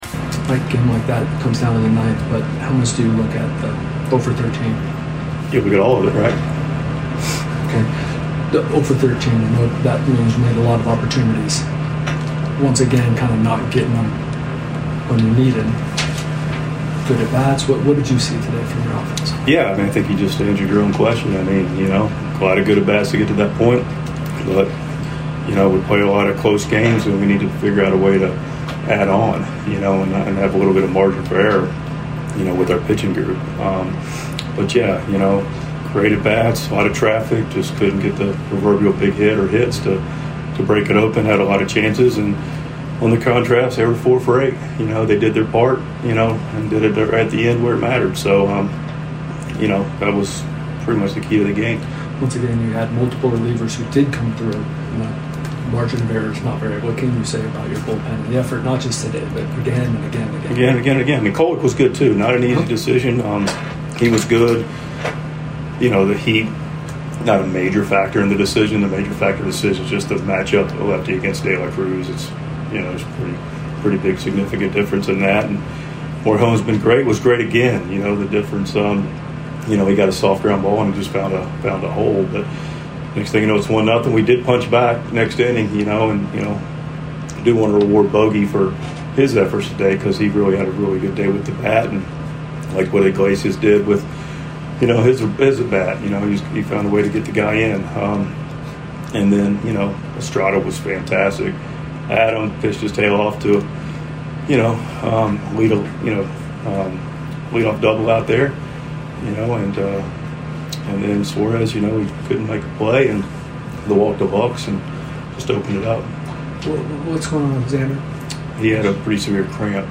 Mike Shildt's postgame reaction after Sunday's 3-2 loss to the Reds.